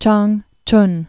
(chängchn) Formerly Hsin·king (shĭnkĭng, -gĭng)